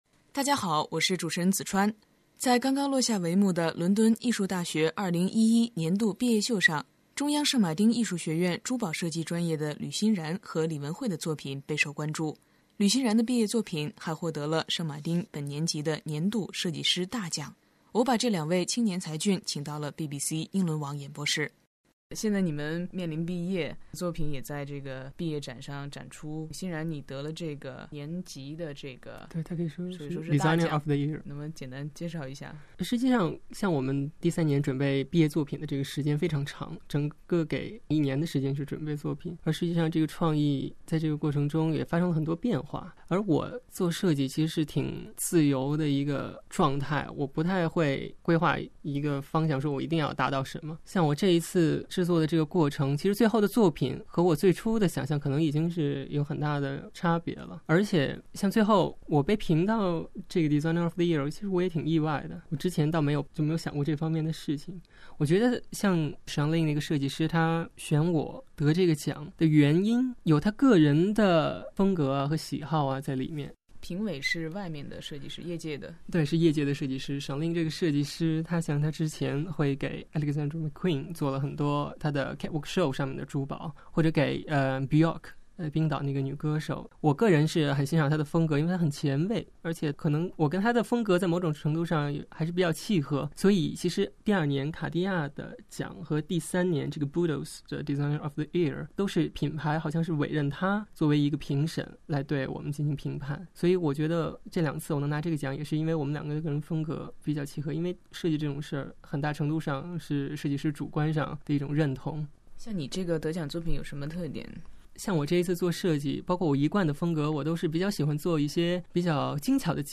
留英访谈：珠宝设计专业